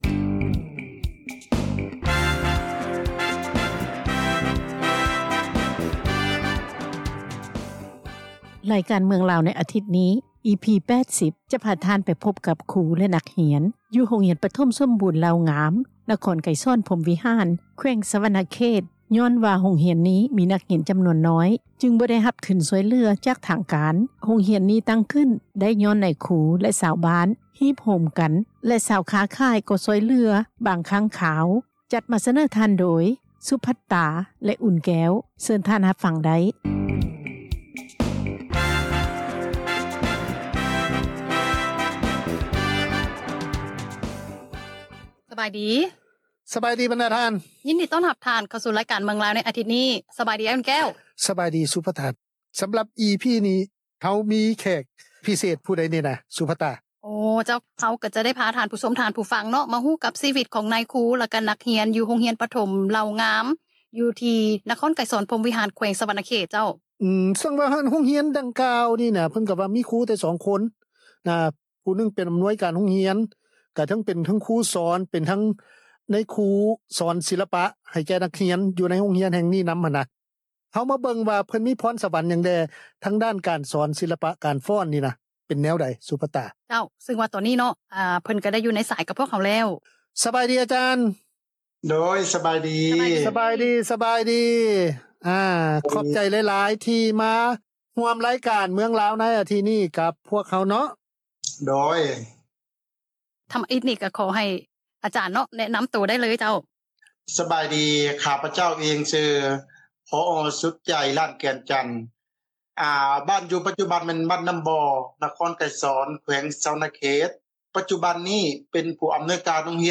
ສຳພາດ ຜູ້ອຳນວຍການໂຮງຮຽນ ທີ່ຕັ້ງຂຶ້ນໄດ້ ຍ້ອນນາຍຄູ ແລະ ຊາວບ້ານ